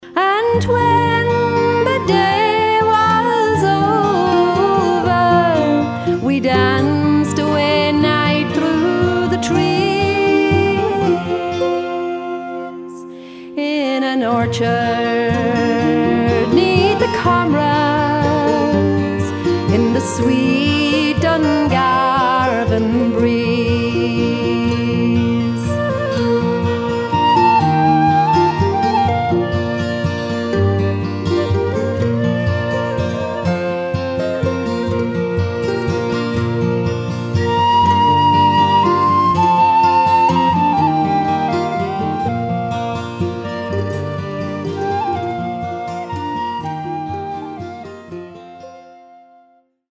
Guitar and vocals
Harp, Whistles and Flute
Rhythm Guitar
Mandolin